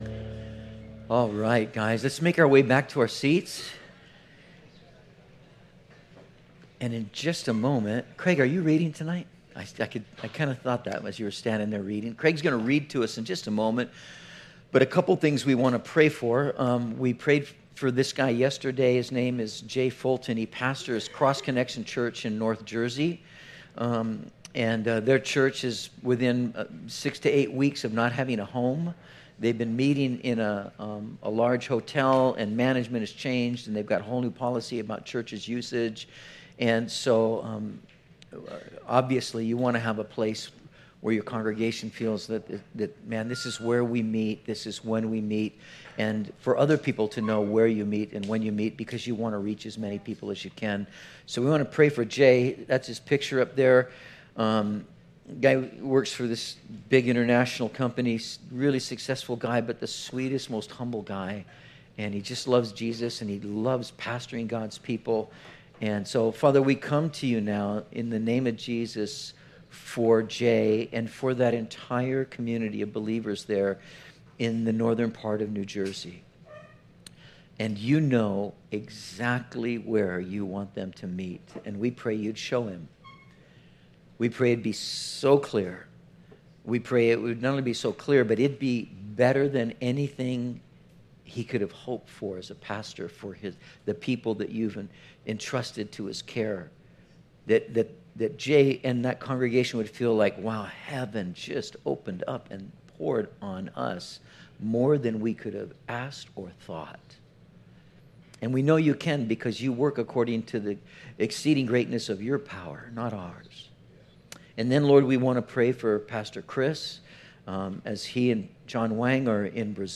06/17/19 Introduction (Part 2) - Metro Calvary Sermons